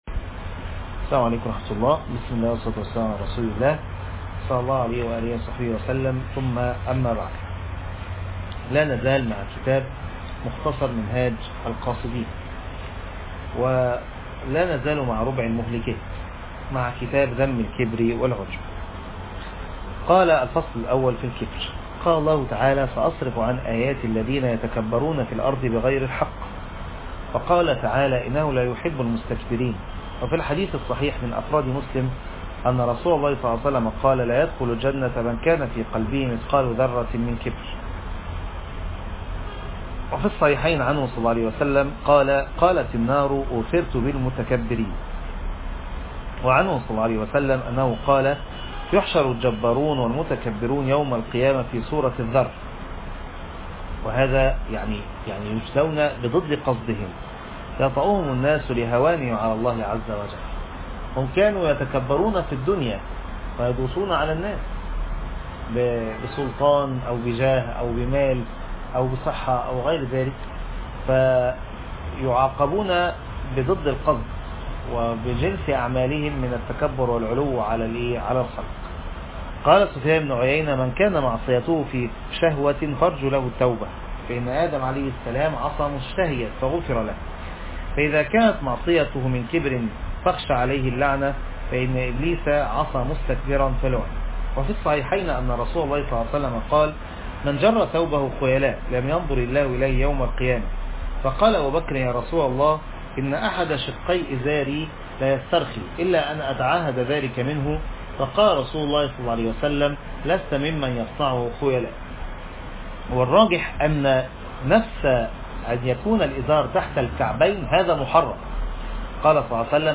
الدرس الرابع عشر - دورة قلب سليم ♥